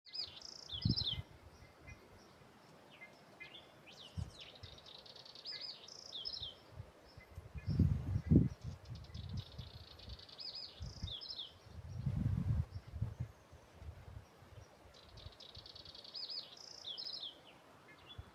Southern House Wren (Troglodytes musculus)
Sex: Male
Life Stage: Adult
Location or protected area: Coyhaique
Condition: Wild
Certainty: Recorded vocal